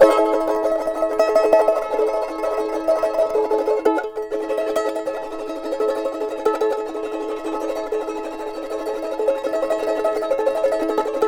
CHAR A#MJ TR.wav